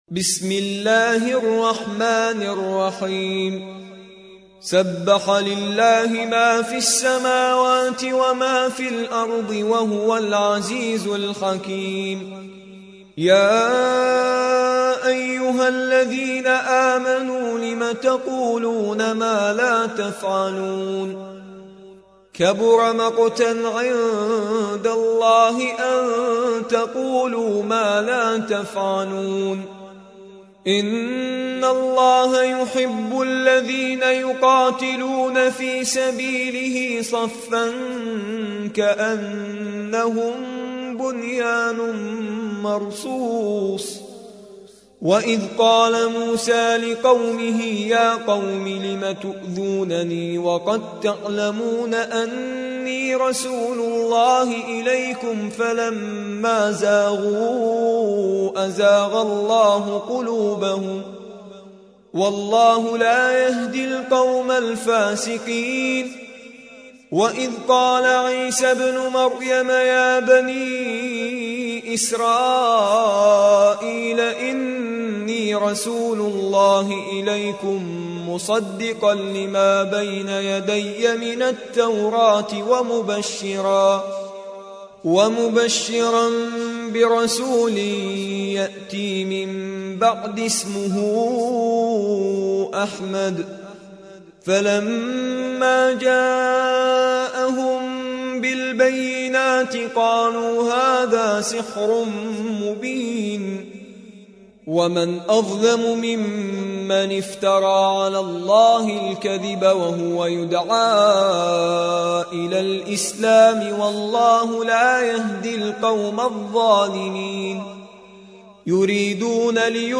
61. سورة الصف / القارئ